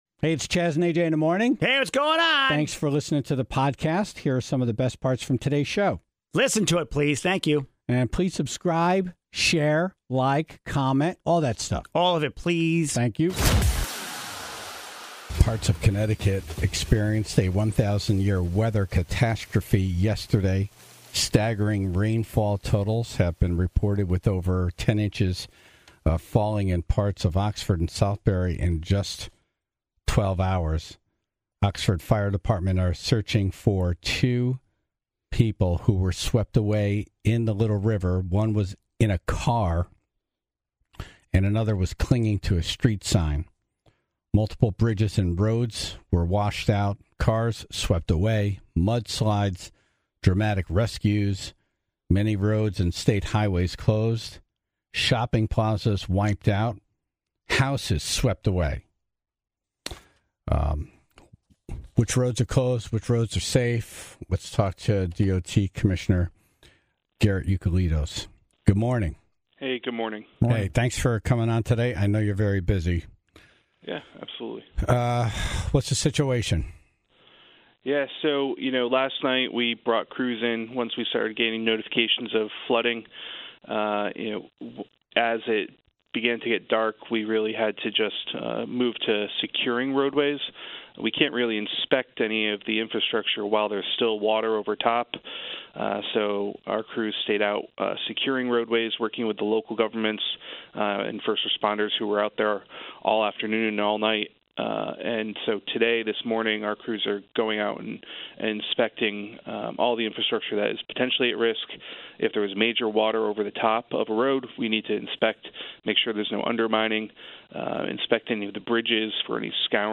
Plus, George Temple, first selectman of Oxford, on the phone to share some of the damage and devastation he's seen in town since last night.